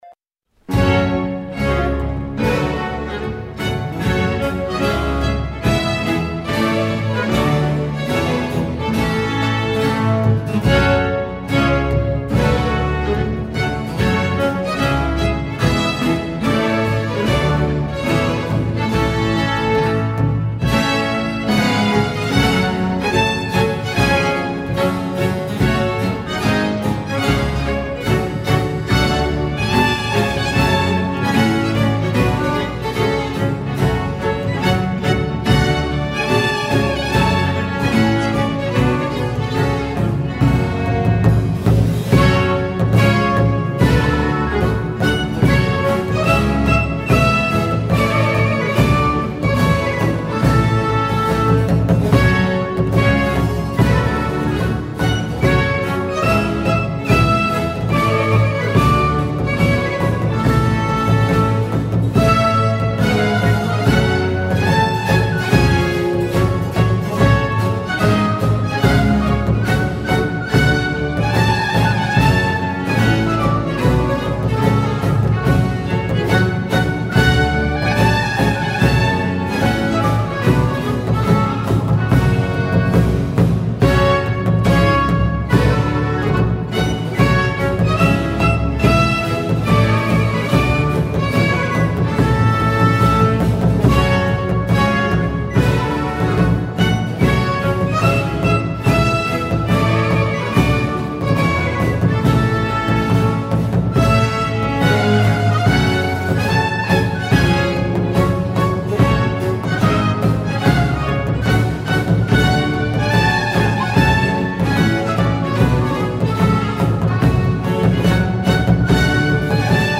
Concerto per violino n. 1 in sol- op. 26
Concerto per orchestra